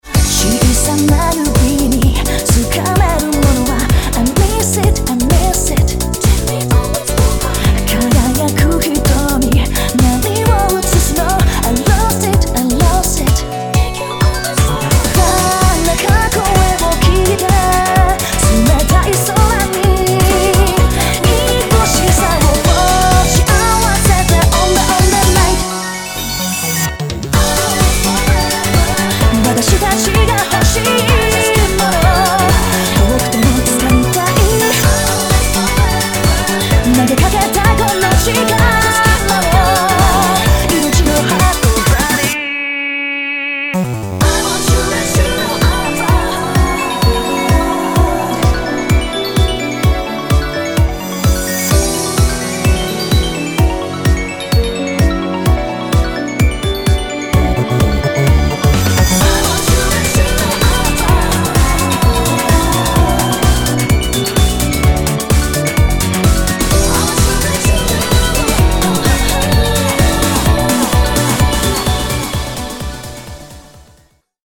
○ジャンルブランド・フィーチャー リミックス音楽CD